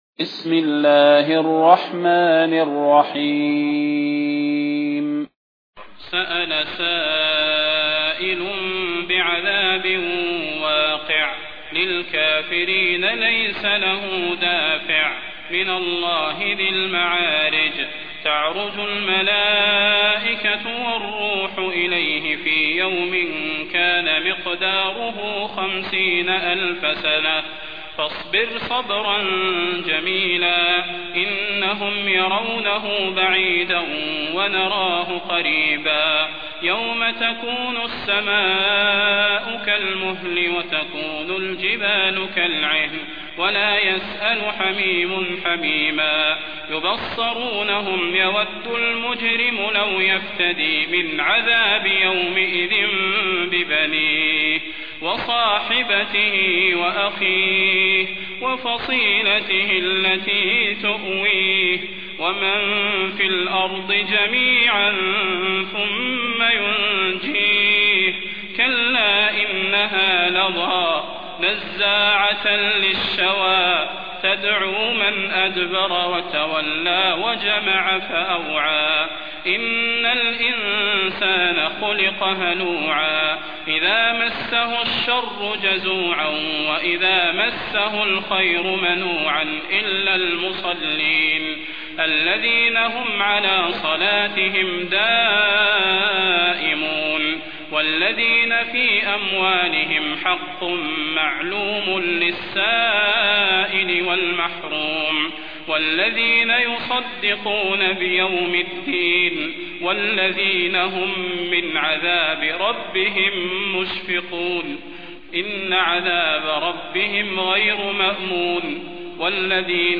المكان: المسجد النبوي الشيخ: فضيلة الشيخ د. صلاح بن محمد البدير فضيلة الشيخ د. صلاح بن محمد البدير المعارج